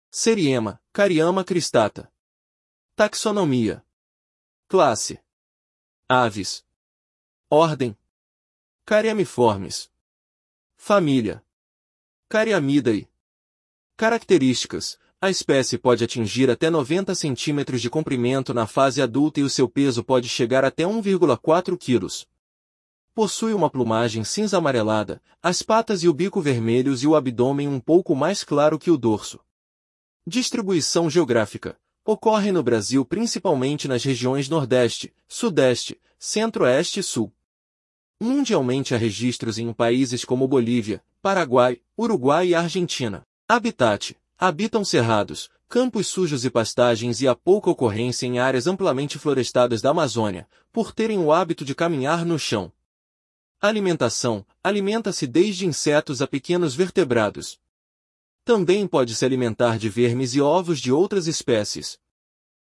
Seriema (Cariama cristata)